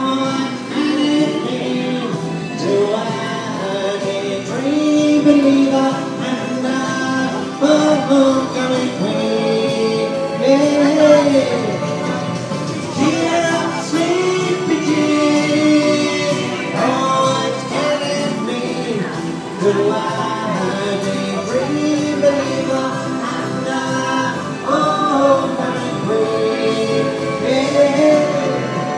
Kyneton's busking finest:
60736-kyneton-s-busking-finest.mp3